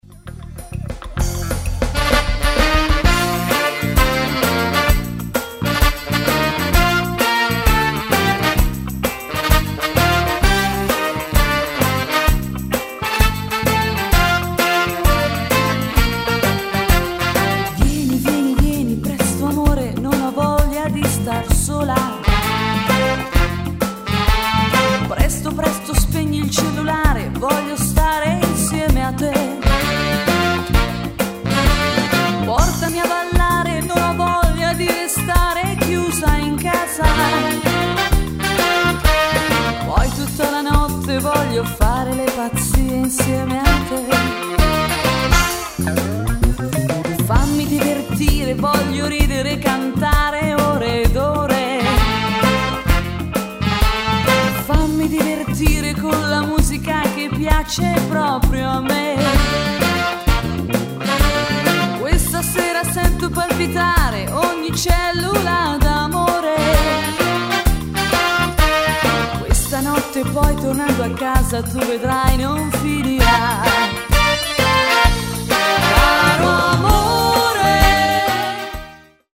Hully gully
Donna